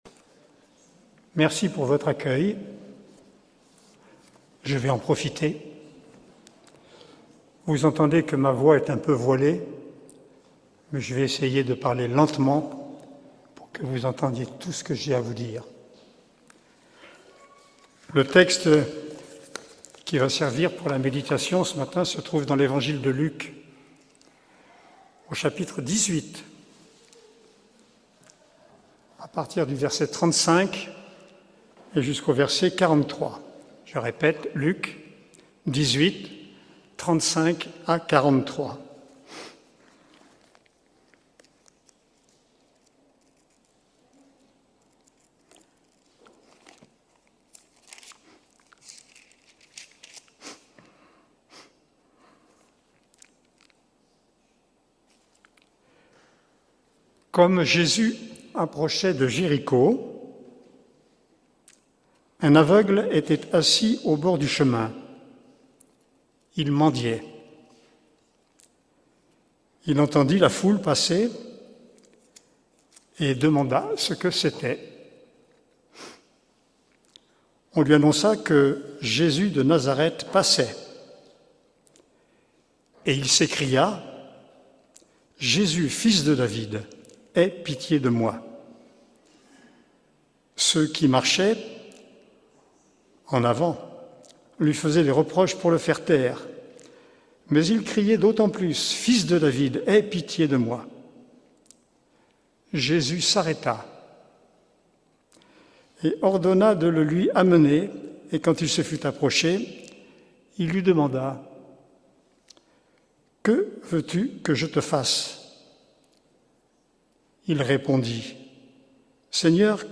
Prédications Audio - Eglise Réformée Evangelique Vauvert